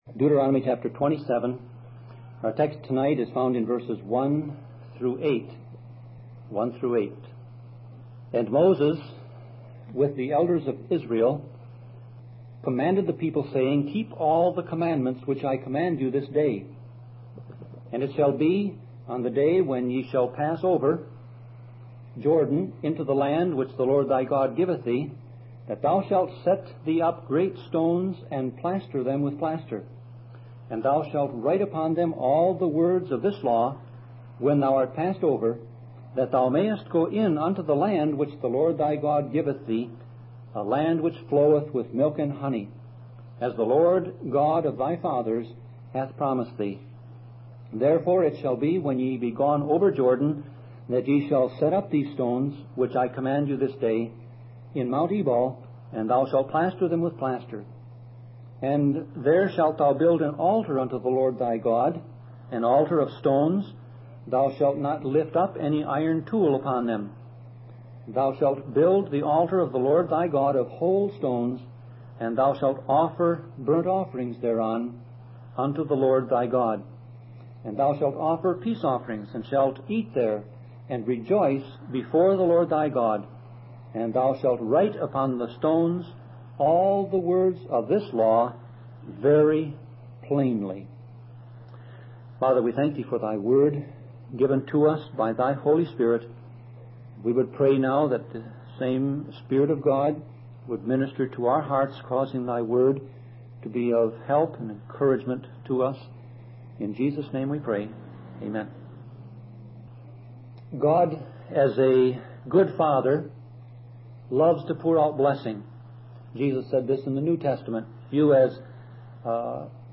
Series: Sermon Audio Passage: Deuteronomy 27:1-8 Service Type